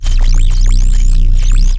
sci-fi_electric_pulse_hum_05.wav